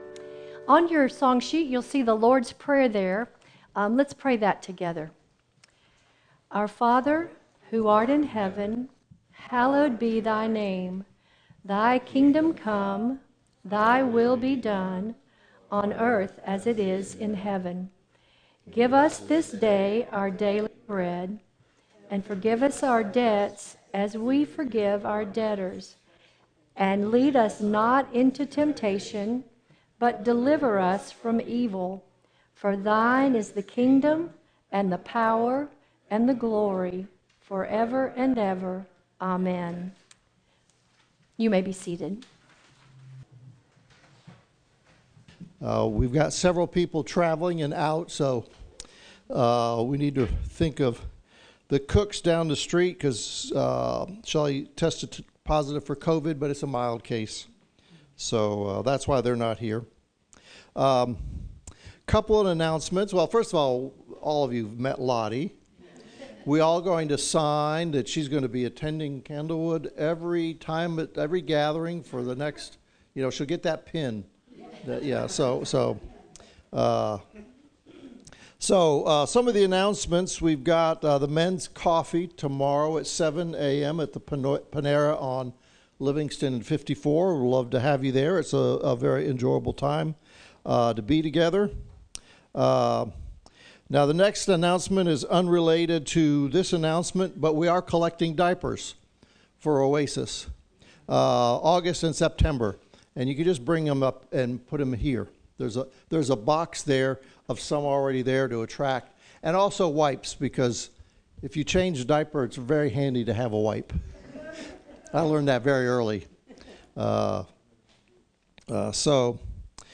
Matthew 5:38-43 Service Type: Gathering We continue to study Jesus’ Sermon on the Mount.